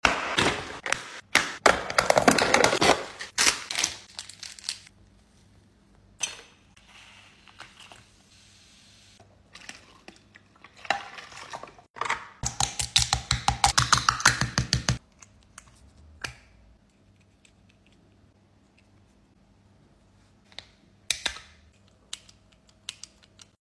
ASMR Treasure x Monster Gold sound effects free download
ASMR Treasure x Monster Gold unboxing!